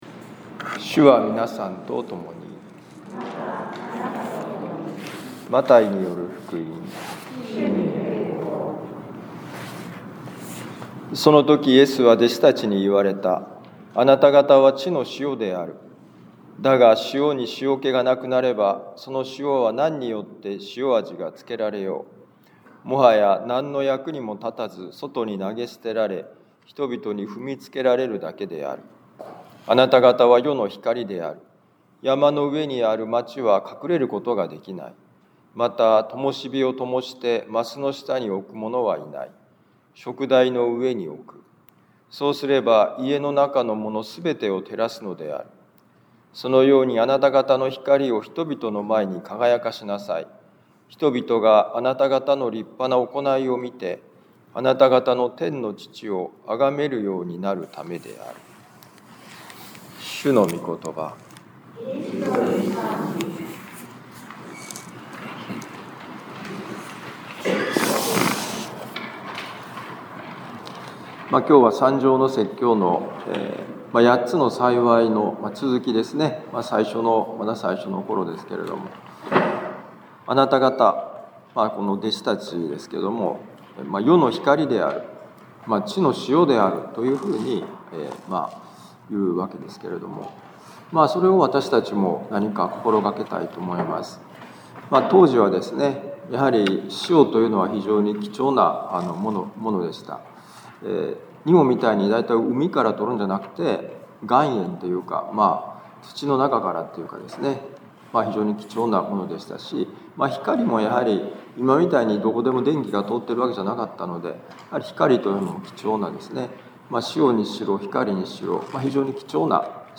マタイ福音書5章13-16節「暗闇をあまねく照らす光」2026年2月8日年間第5主日防府カトリック教会